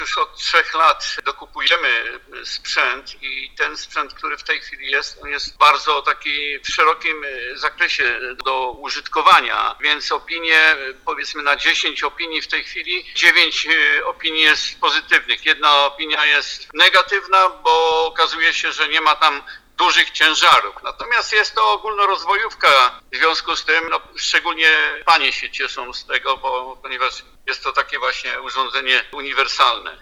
– Komentuje inwestycję Stanisław Szymczak, burmistrz Chociwla